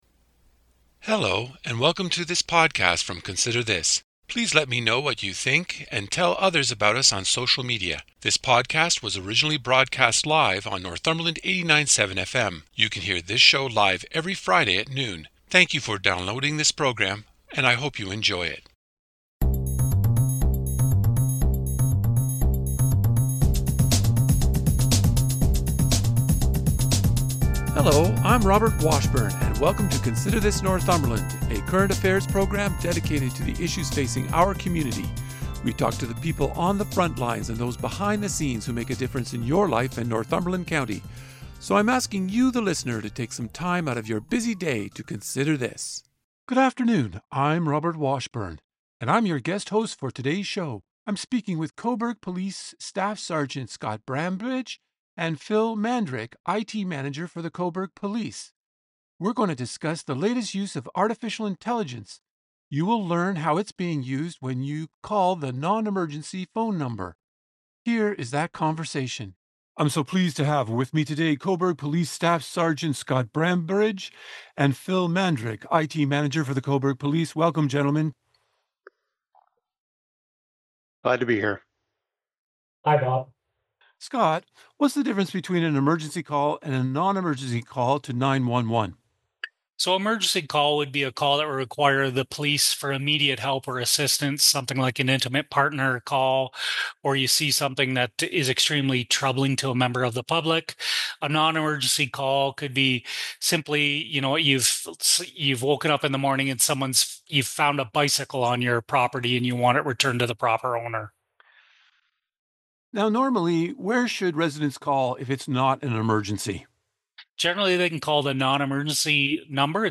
This interview was aired on the Northumberland @5 show.